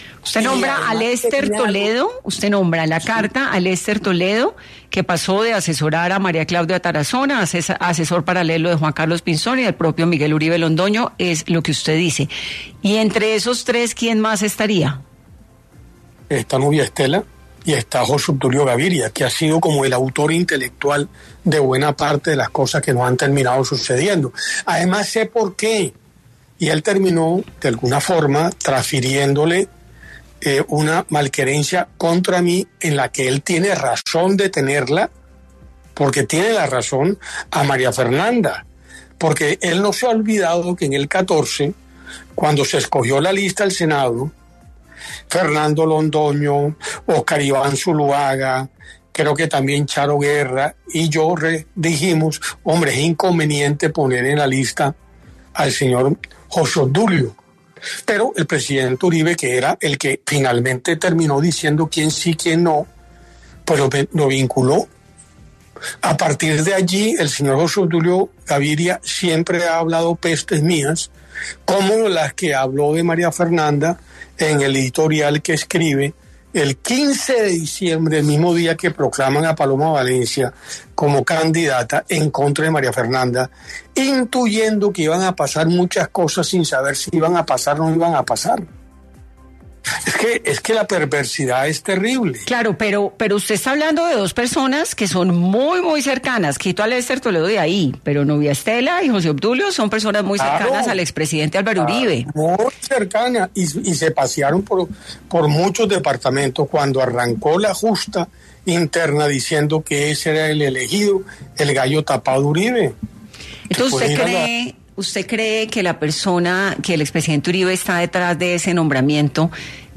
El presidente de Fedegán y esposo de María Fernanda Cabal, José Félix Lafaurie, pasó por los micrófonos de Dos Puntos de Caracol Radio para hablar acerca de la escisión que propusieron en el Centro Democrático tras anunciar su salir de la colectividad.